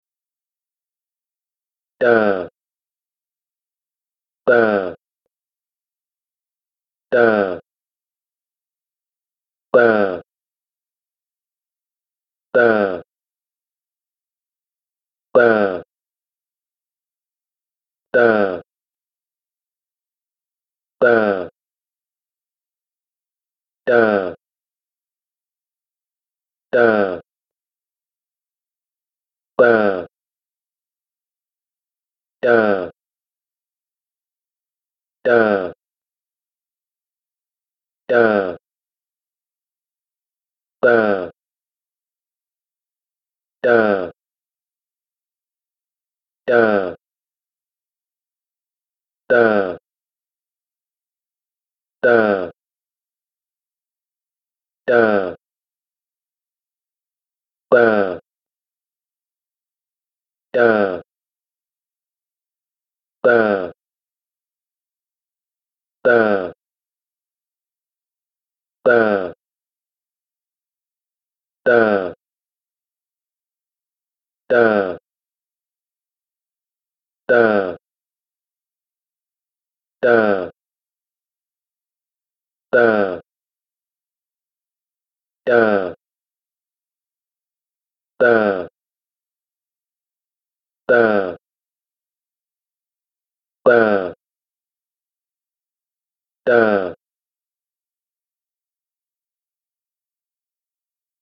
Randomized ba-da continuum (stimuli adapted from Menn, 2011)
A randomized sample of 35 stimuli along the ba-da continuum.